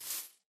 step / grass4